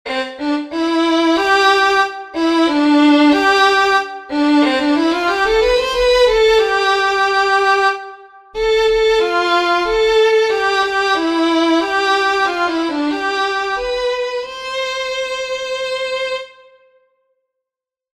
Melodic reading practice
Exercise 1: 3/4 time signature.
melodic_reading_1.mp3